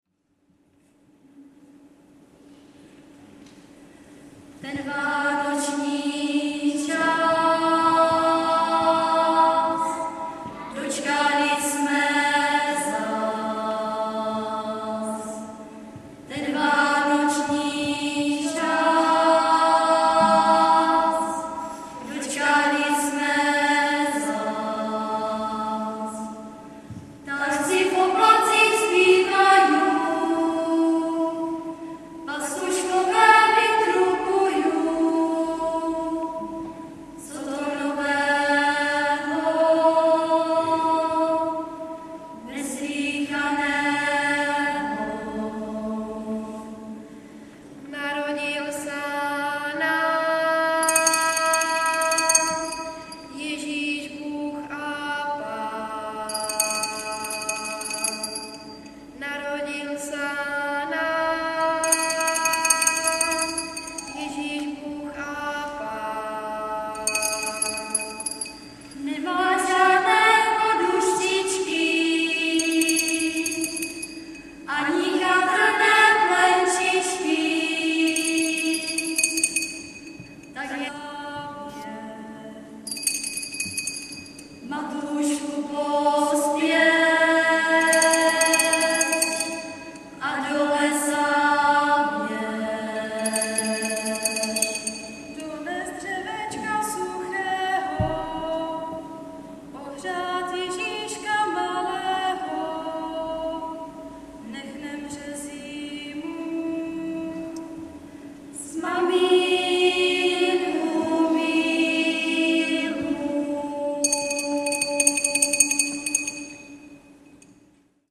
ADVENTNÍ KONCERT
Březová … kostel sv. Cyrila a Metoděje ... neděla 9.12.2007
... pro přehrátí klepni na názvy písniček...bohužel ně skomírala baterka v MD ...